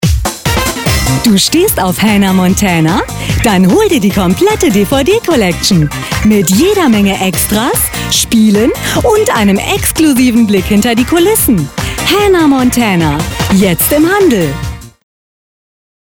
Sprechprobe: Sonstiges (Muttersprache):
german female voice over artist, young voice